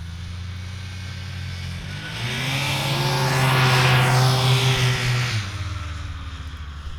Snowmobile Description Form (PDF)
Internal Combustion Subjective Noise Event Audio File (WAV)